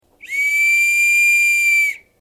Боцманский свисток ВМФ СССР (б/у)
Сделан свисток из сплава меди и латуни с высоким сопротивлением износу.